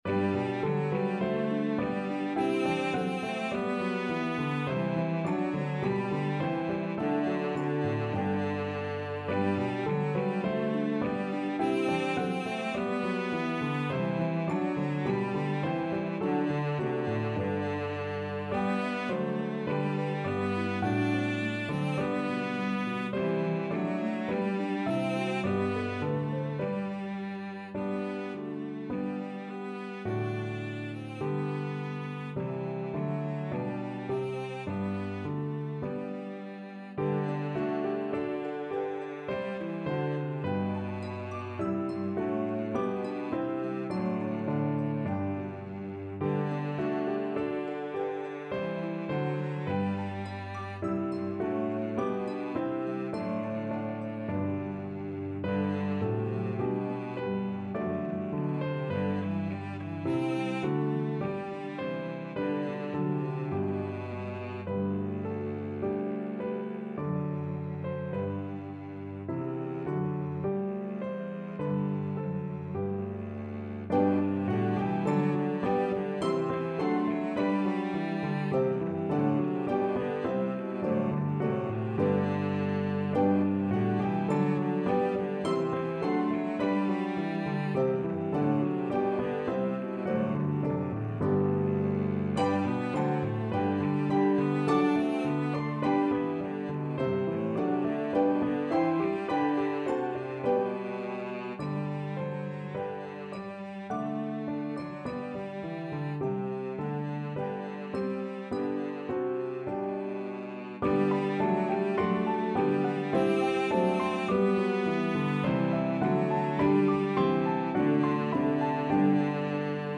The lyrics to the German Epiphany hymn